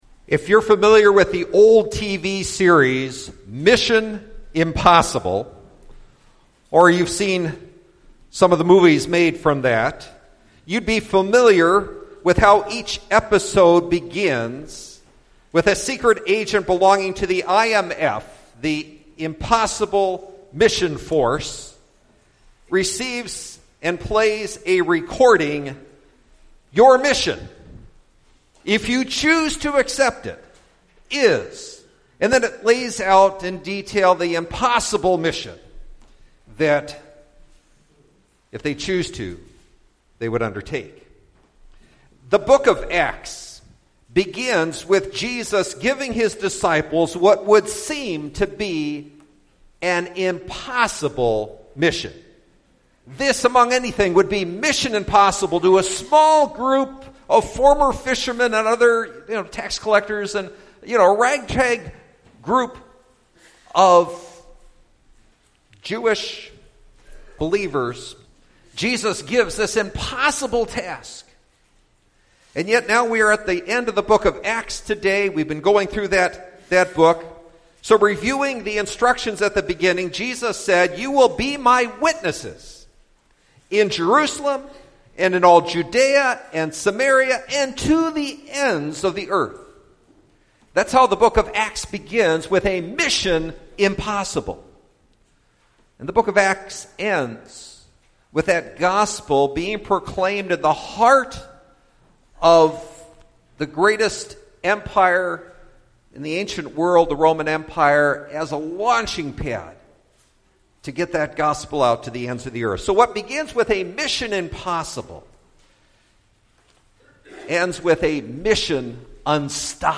Witness for Jesus - The Book of Acts Listen To Sermon